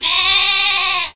Cri de Moumouton dans Pokémon HOME.